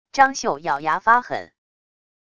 张绣咬牙发狠wav音频